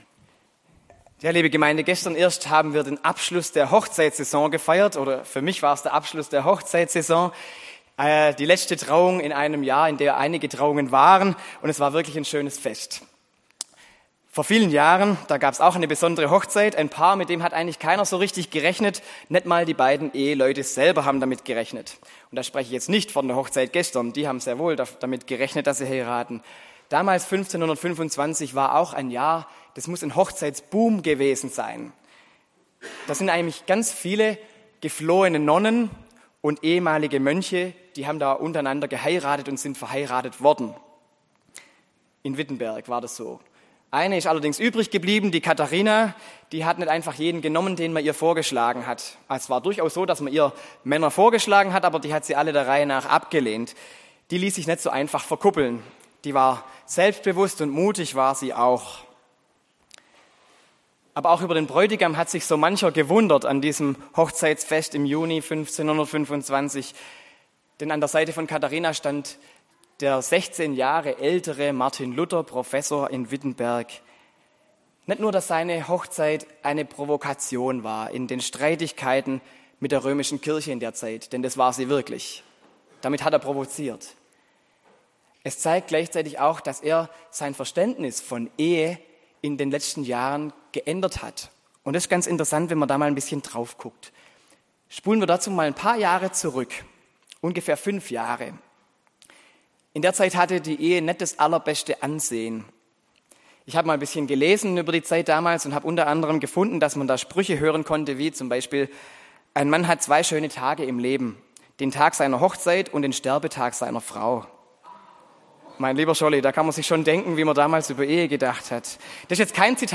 Predigt vom 29.